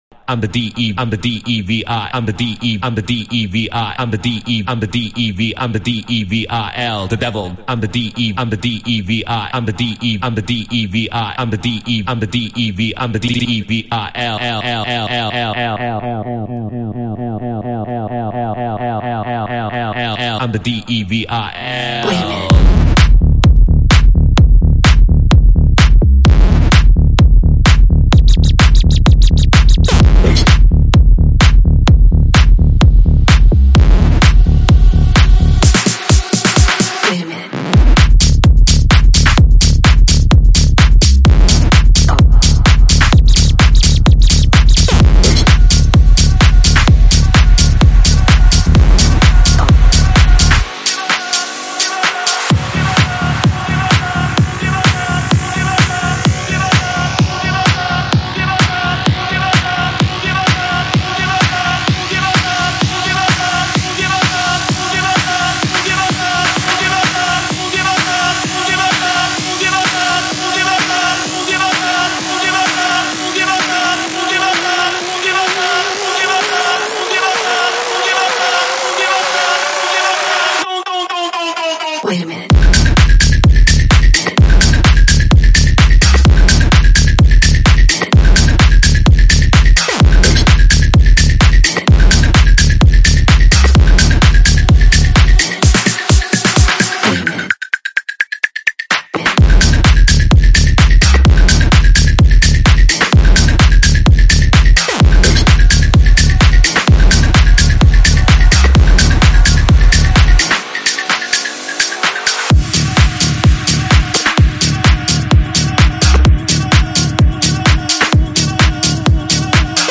试听文件为低音质，下载后为无水印高音质文件